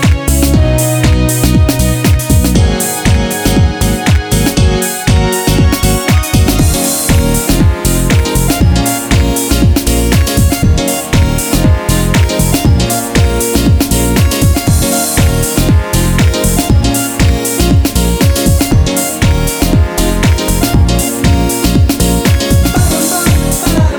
Dance Mix With no Backing Vocals Dance 4:13 Buy £1.50